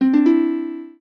lyre_cde.ogg